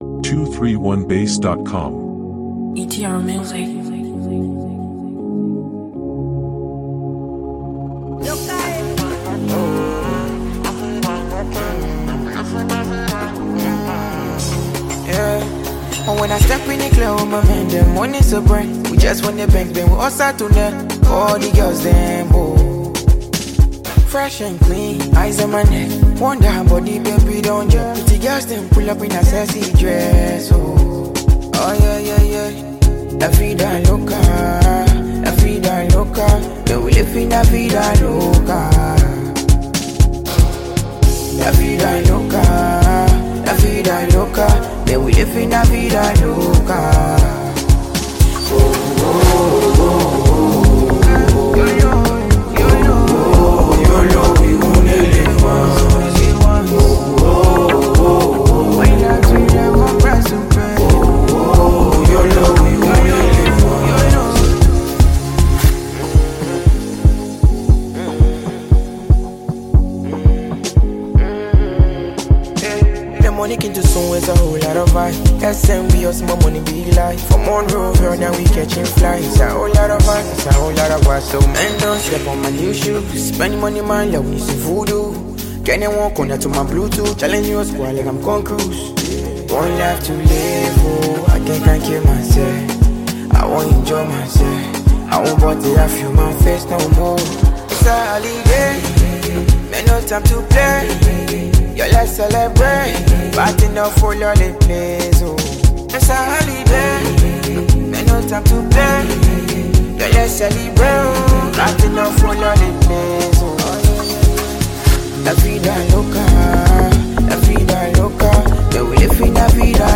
think catchy hooks, smooth production
It’s a cool jam for good times, parties, or just unwinding.